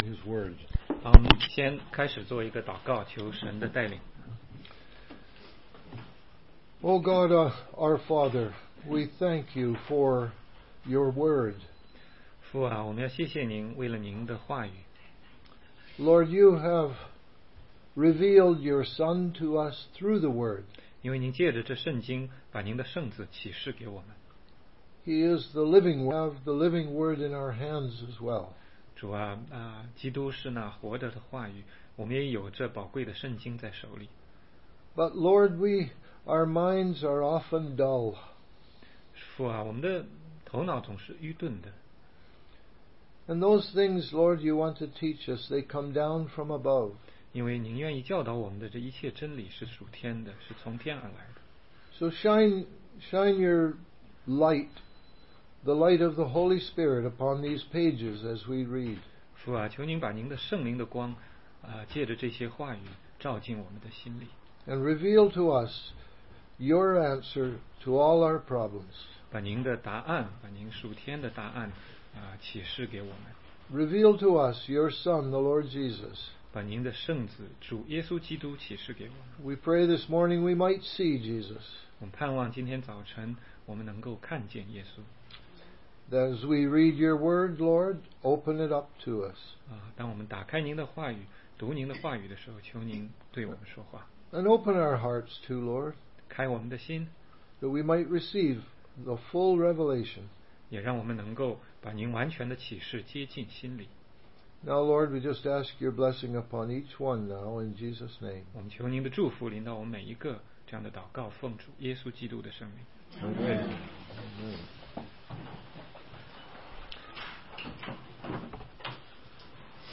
16街讲道录音 - 约翰福音8章13节-20节